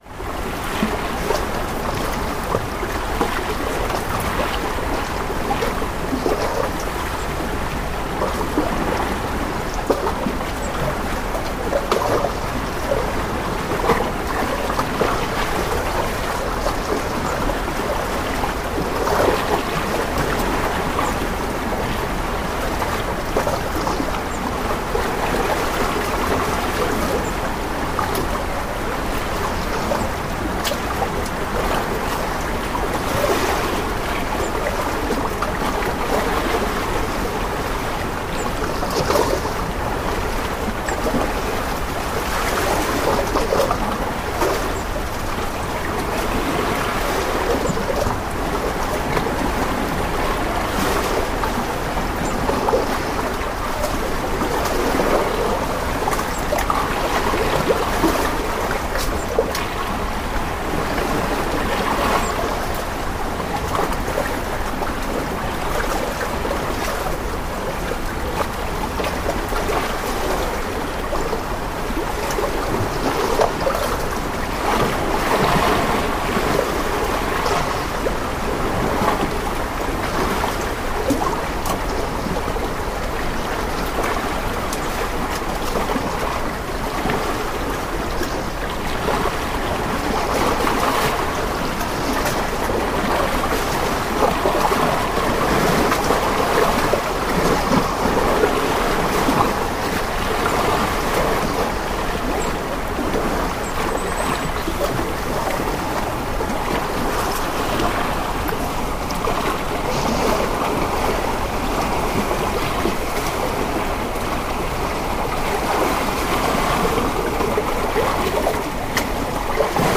Waves on the jetty, Flat Holm Island
Waves breaking on the boat landing jetty, Flat Holm Island.